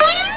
Sons de humor 47 sons
poin2.wav